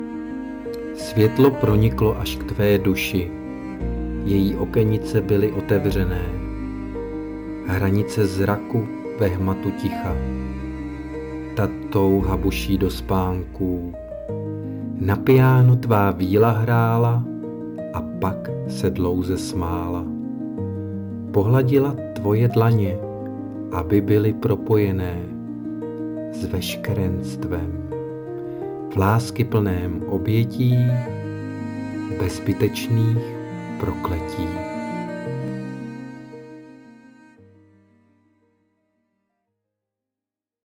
hudba: AI (by SUNO)
Moc hezké ,příjemný přednes s hudbou.
Líbezná báseň, libým hlasem